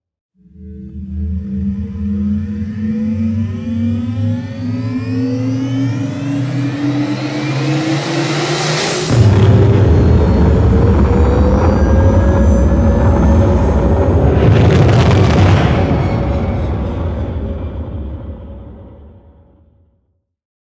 otherlaunch2.ogg